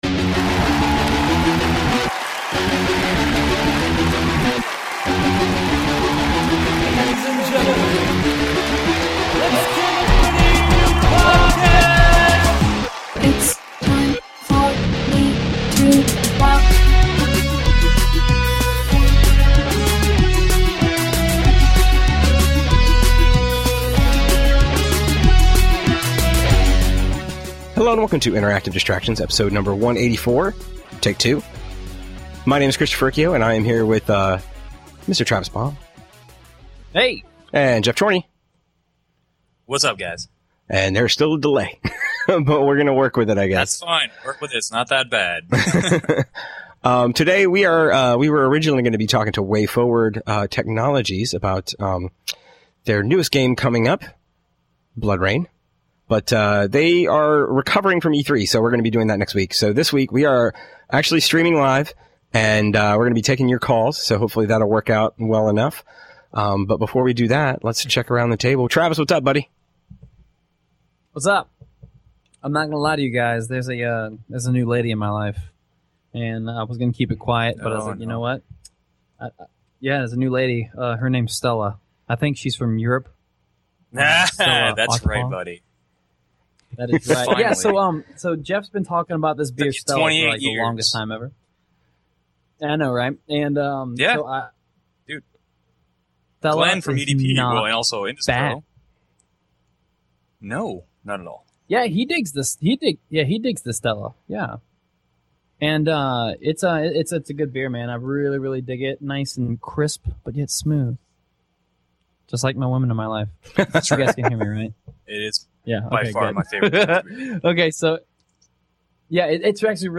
Normal crew in this episode where we test out recording the show while streaming live on Thursday night.